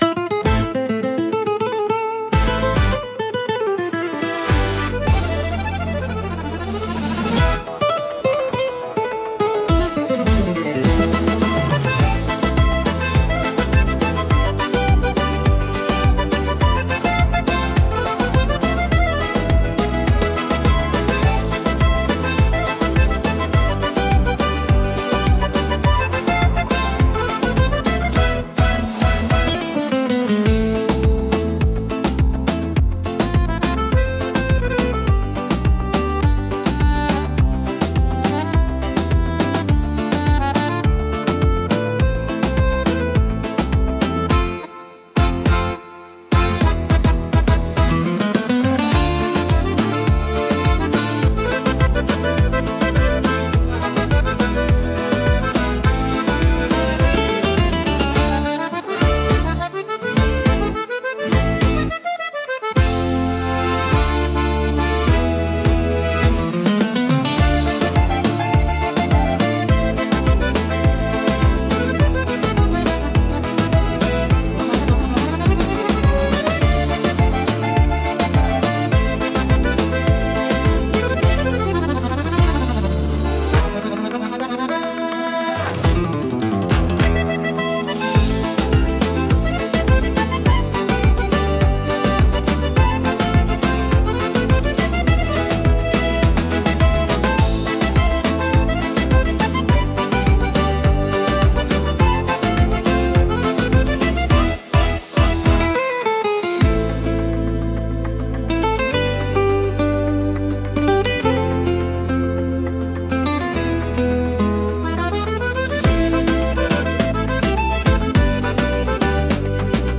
感情の深みと躍動感を強く伝え、力強く印象的な空間づくりに貢献します。